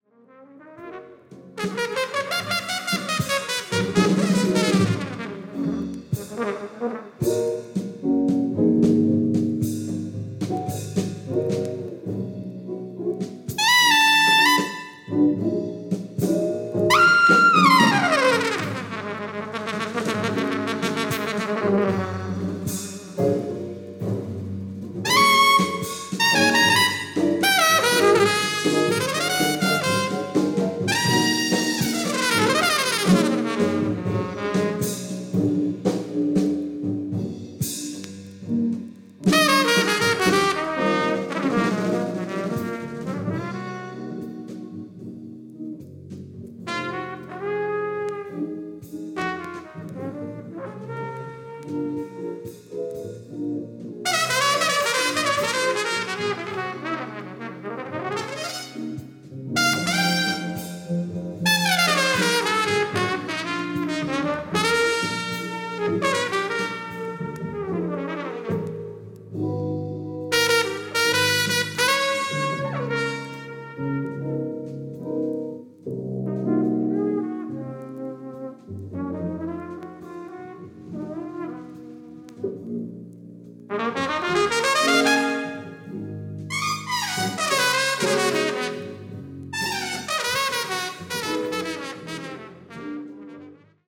75年・根室公民館でのライブ録音盤です。
Piano
Saxophone
Drums
Electric Guitar
Bass
Trumpet, Flugelhorn